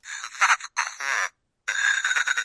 描述：录制的遥控装置，是一块声板，包含了《海狸和烟蒂头》的原始声音，随书附送的"这很糟糕，改变它"。酷！